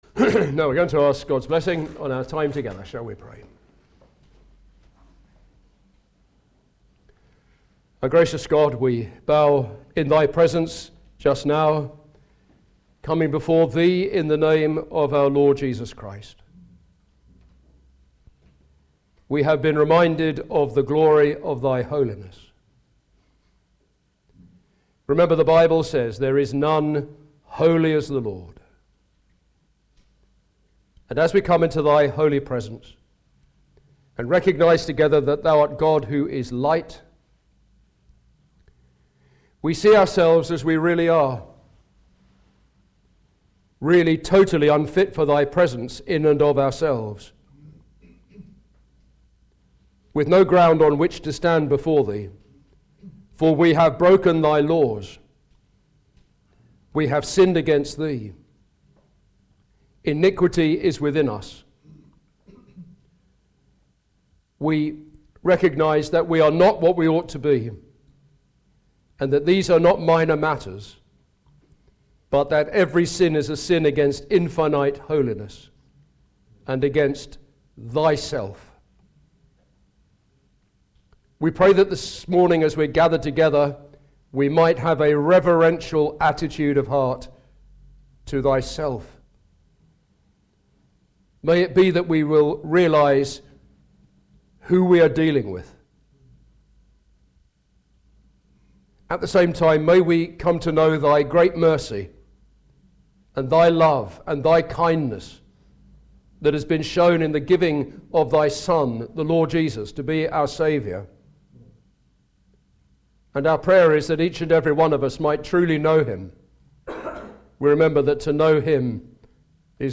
Service Type: Gospel